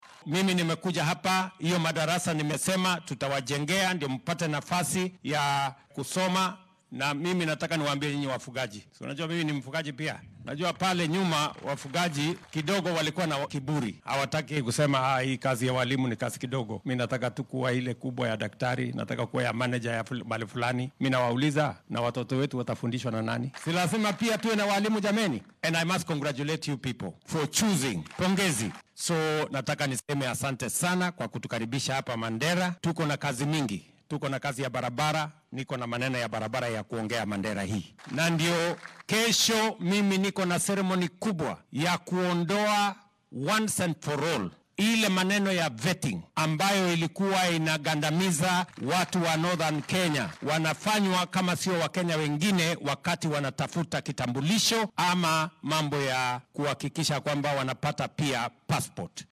Madaxweynaha ayaa xilli uu hadal ka jeedinayay machadka tababarka macallimiinta ee Mandera sheegay in berri uu guddoomin doono munaasabad ballaaran oo meesha looga saaraya turxaan bixinta la mariyo shacabka gobolka marka ay codsanayaan aqoonsiga qaran iyo baasaboorka.